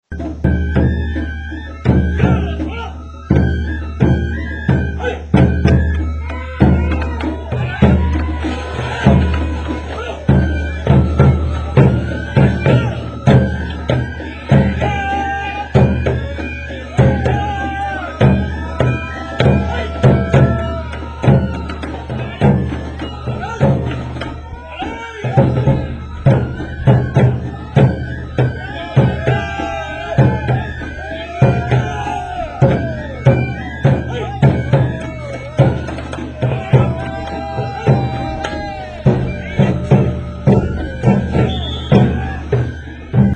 尾張の山車囃子～尾張・三河
■岩倉市下本町